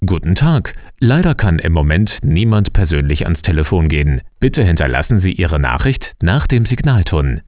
sprecher24de_begruessung.wav